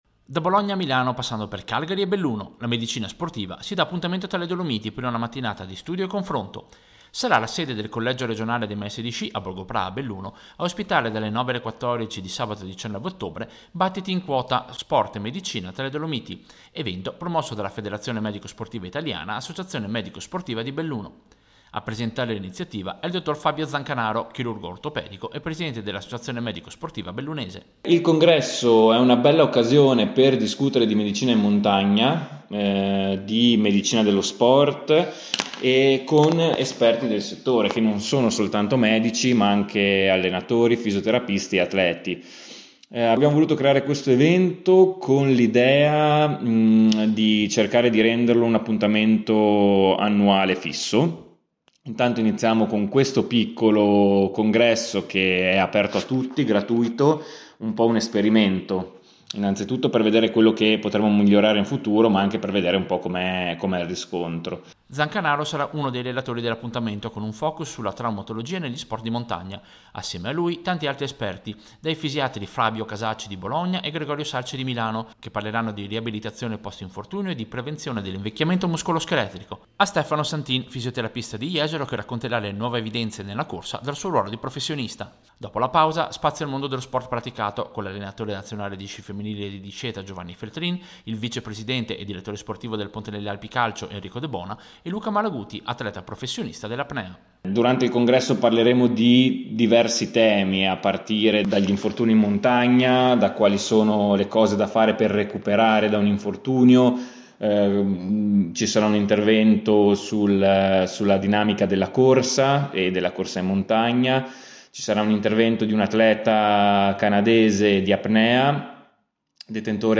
Servizio-Battiti-in-quota.mp3